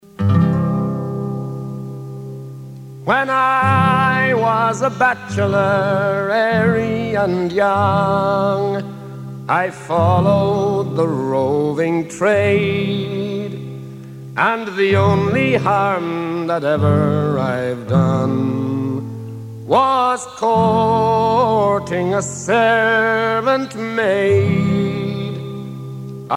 danse : reel